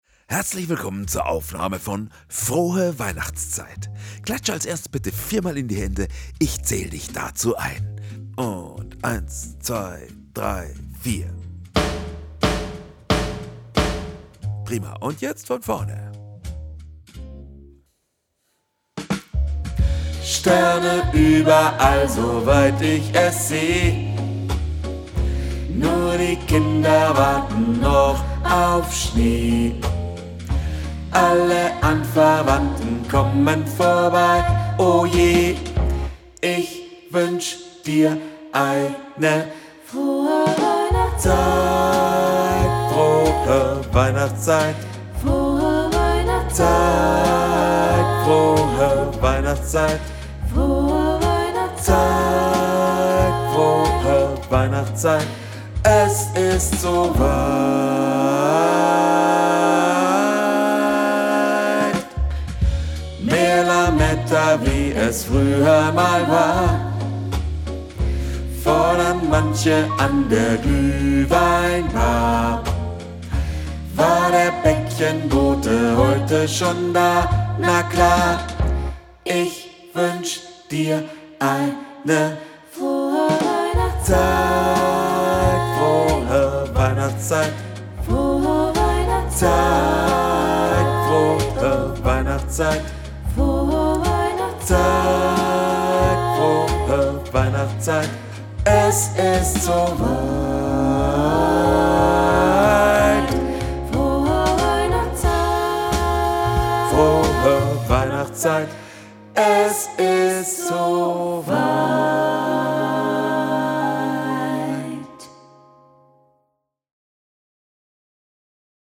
Playback für Video - Bass
Frohe_Weihnachtszeit - Playback fuer Video - Bass.mp3